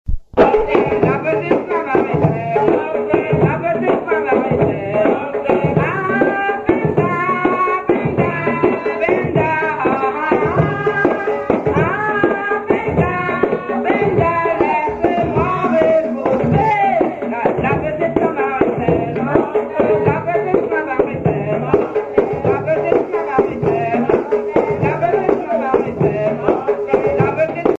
groupe folklorique Les Immortelles
Sinnamary
danse : grajé (créole)
Pièce musicale inédite